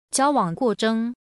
Jiǎo wǎng guò zhèng.